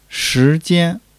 shi2--jian1.mp3